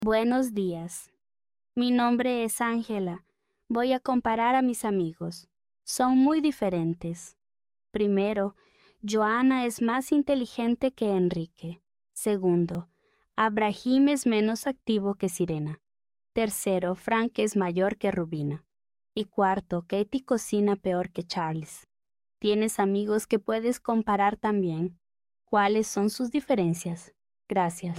Listen as Angela, a colleague of Luis’s, compares her friends in the following 4 slides.